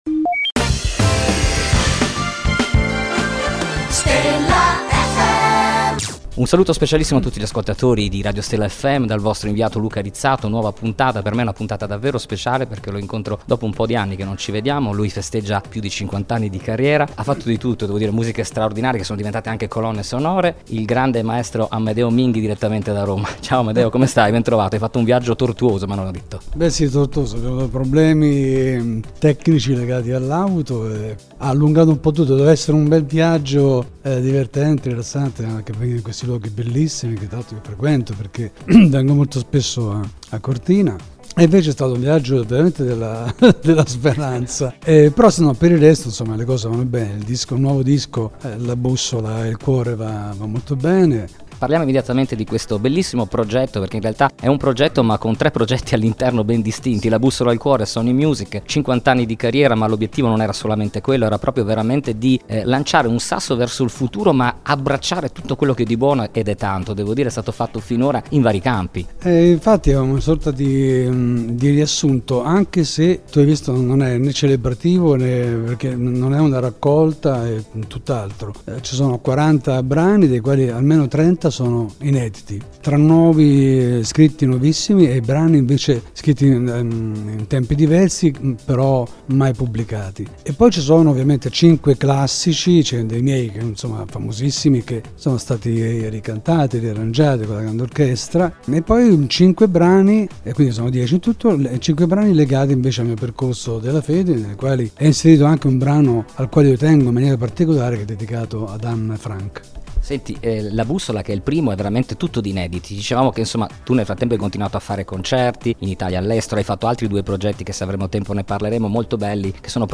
Intervista Amedeo Minghi | Stella FM